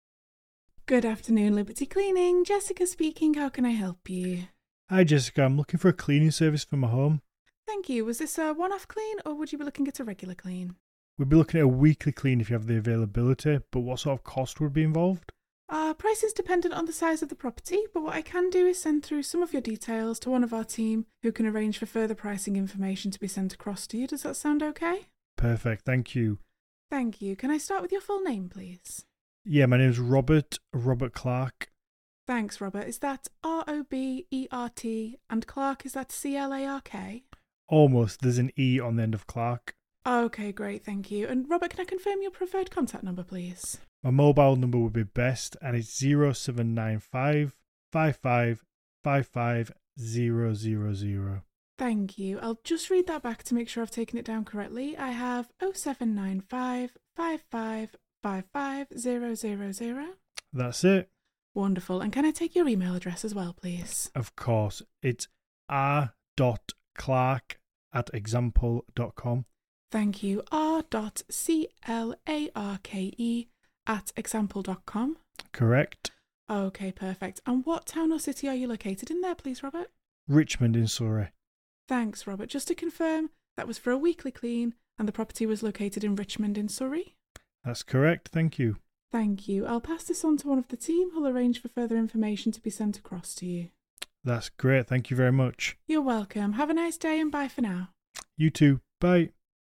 Experience the level of care and professionalism
business-telephone-answering-service-sample-call-MyReceptionist.mp3